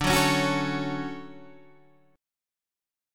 D Major 7th